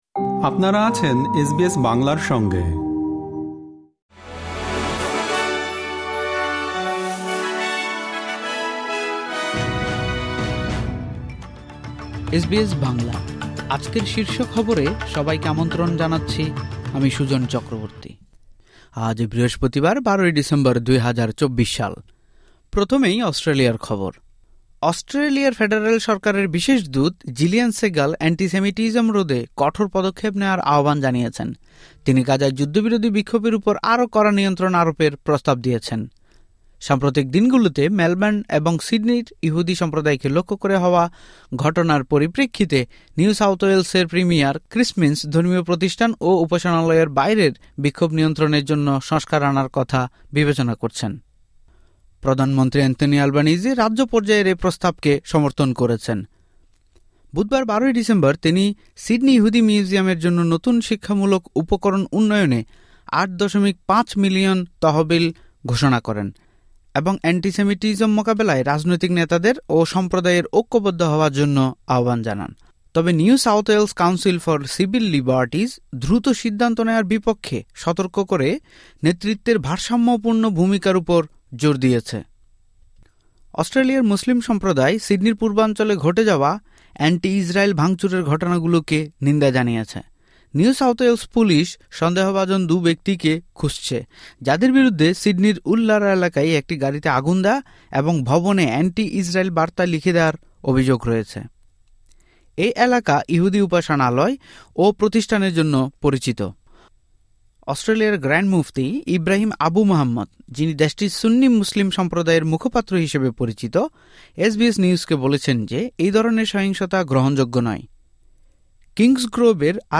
এসবিএস বাংলা শীর্ষ খবর: ১২ ডিসেম্বর, ২০২৪